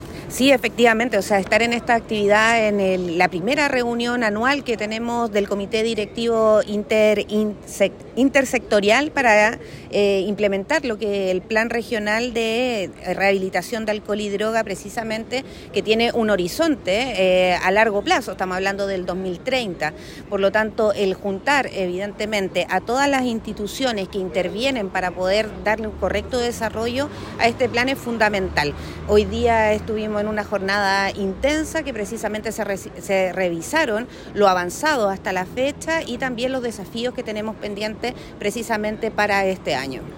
Paulina-Mora-Seremi-de-Gobierno-online-audio-converter.com_.mp3